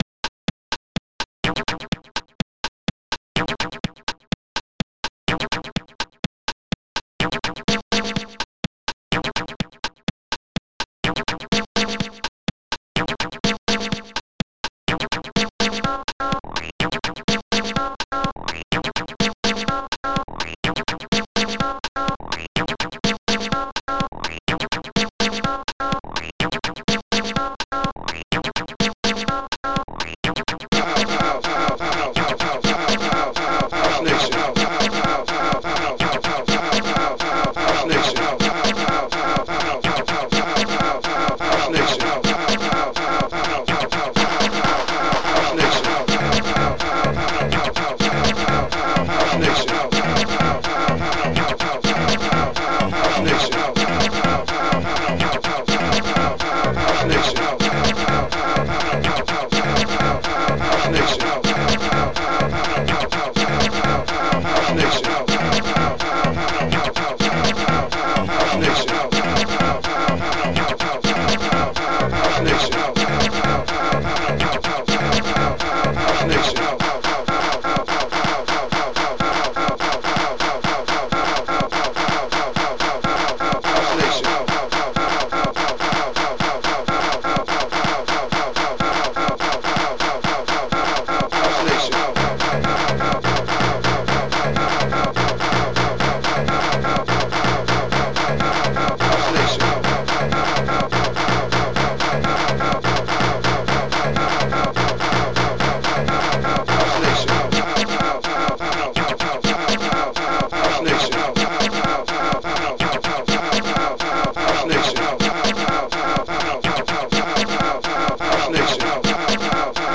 st-17:HN-BassDrum
st-17:House
st-17:Acid1
ST-17:Clap5
ST-17:Bass2